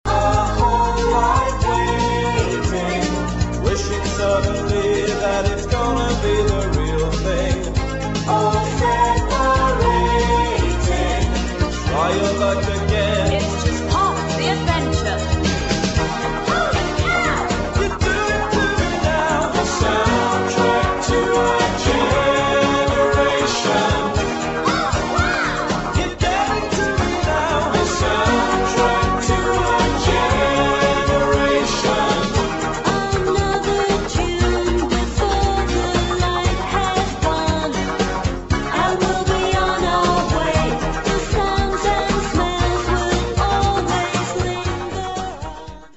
[ NEW WAVE | DISCO ]